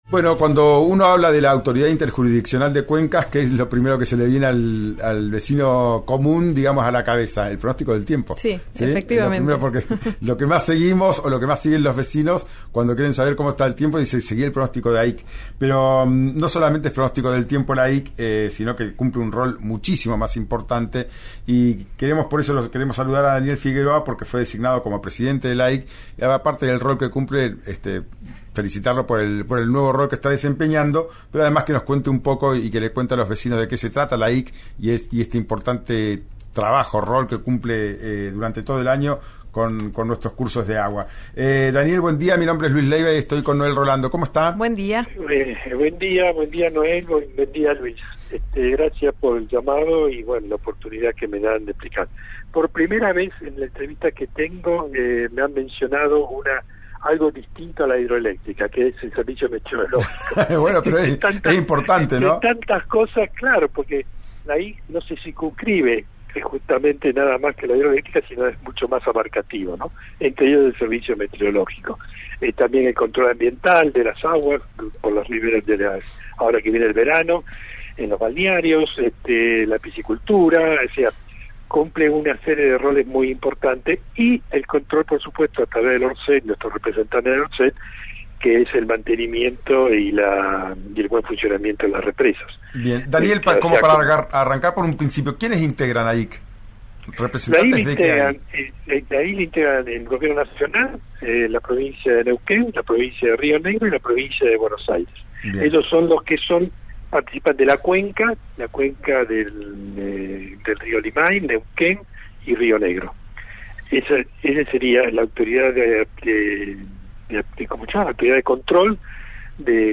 Escuchá a Daniel Figueroa en «Ya es tiempo» por RÍO NEGRO RADIO: